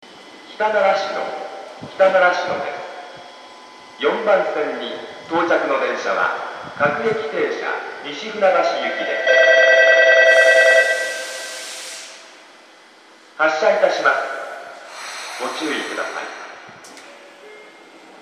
駅放送
4番線到着発車   -- 東日本大震災発生後数日間続いた、線内折り返しダイヤにおける到着放送です。乗換案内が省略されています。